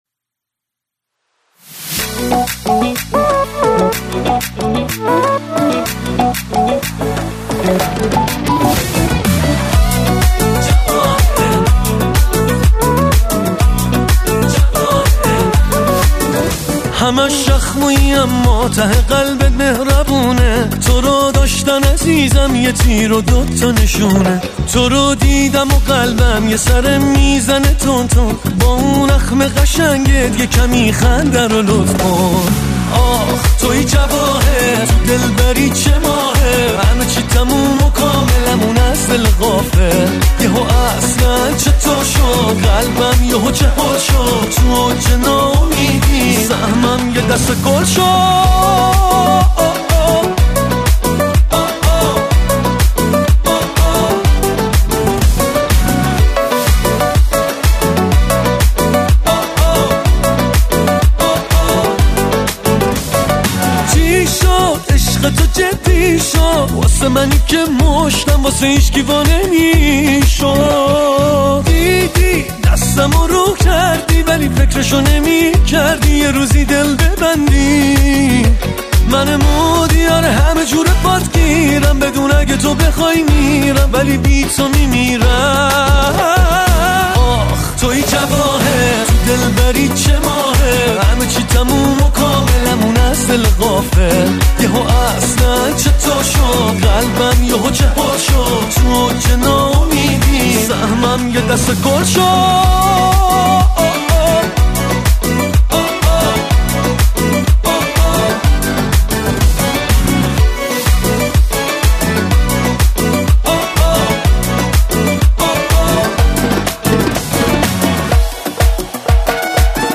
گیتار الکتریک
گیتار بیس